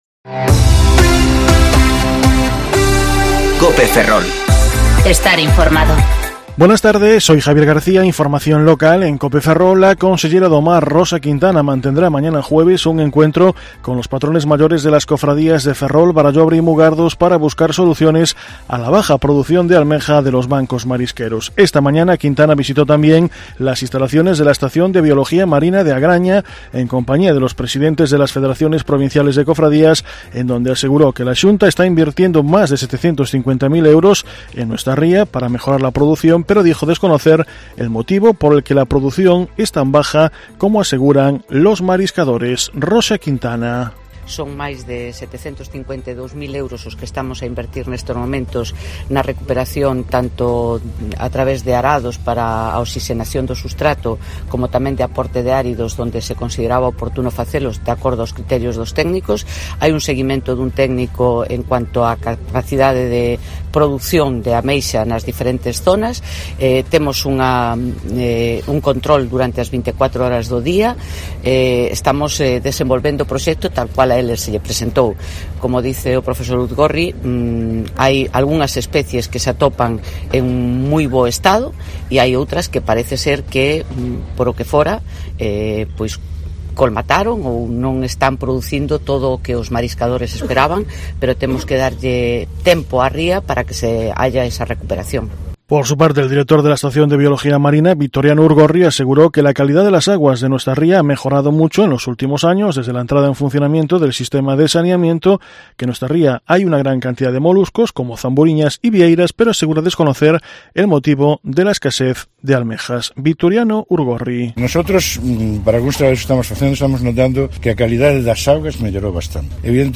Informativo Mediodía COPE Ferrol 12/2/2020 (De 14,20 a 14,30 horas)